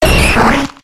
Audio / SE / Cries / OMANYTE.ogg